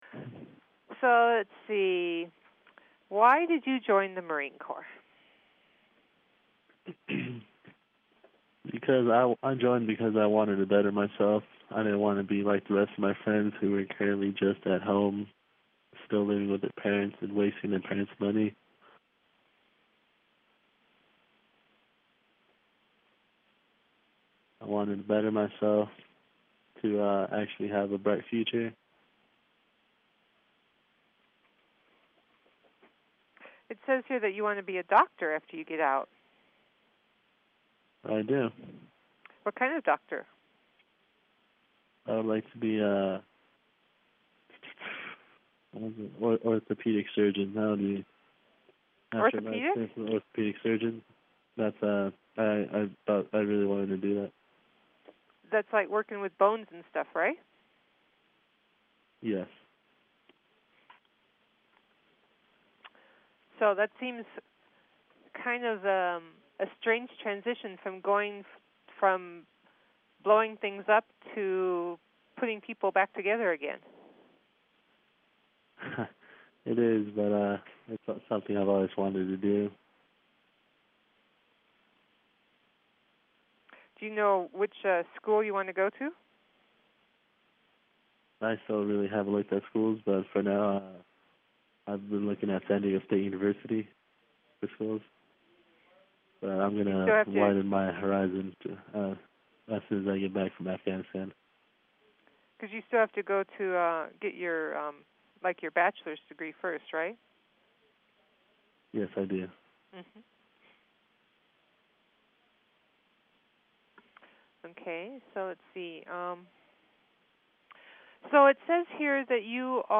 talks to a KCDZ 107.7 FM Online reporter in Yucca Valley, Calif.